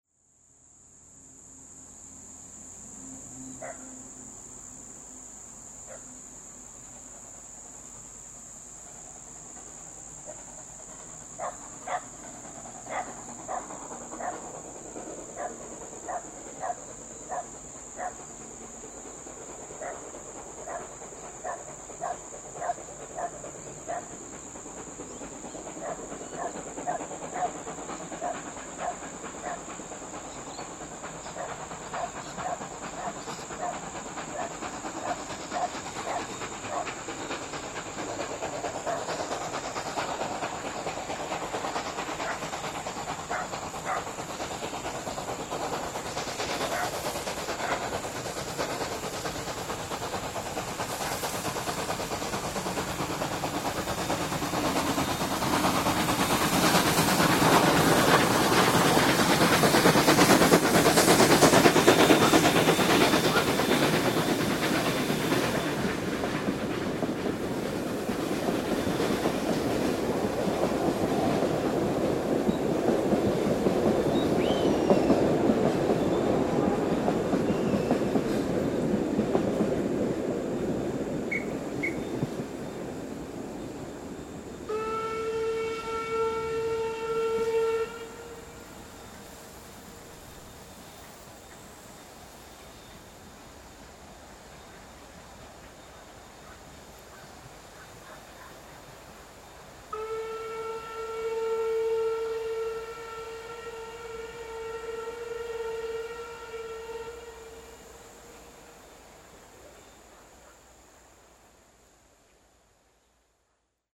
We first caught C17 802 near Monkland, heading up grade after crossing the Deep Gully bridge.